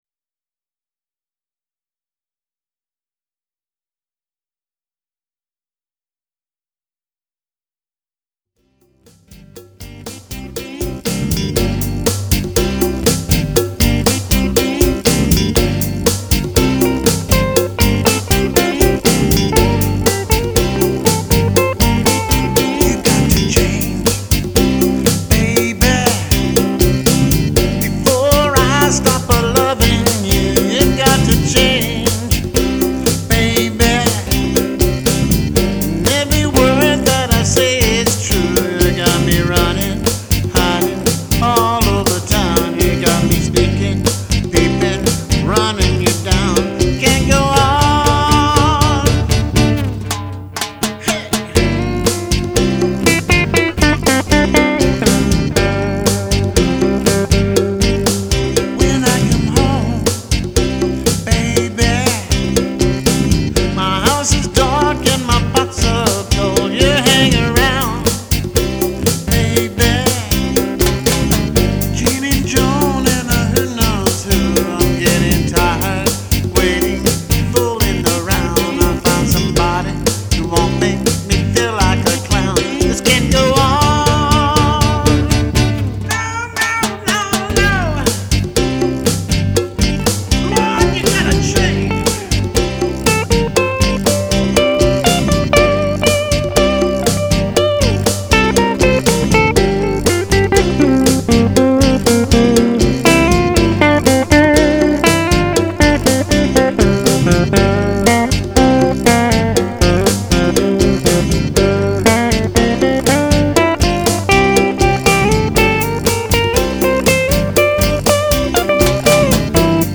AT THE STAE FAIR OF TEXAS 2010